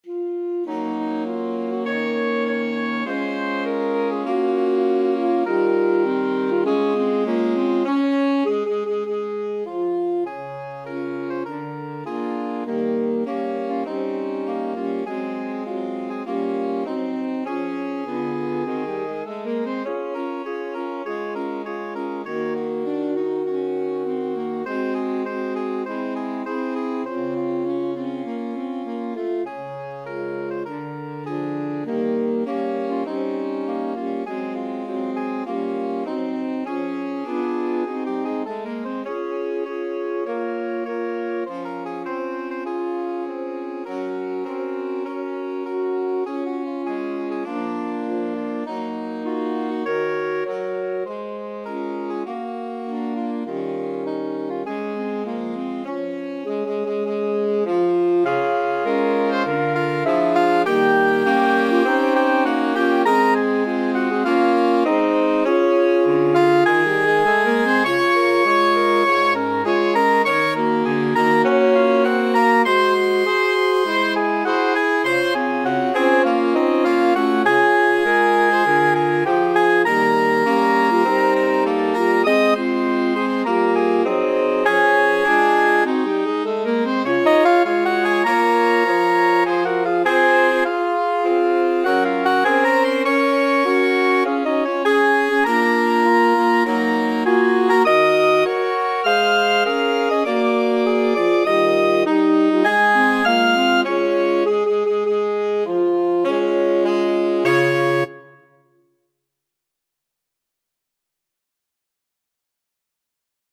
4/4 (View more 4/4 Music)
Moderato = c. 100
Jazz (View more Jazz Saxophone Quartet Music)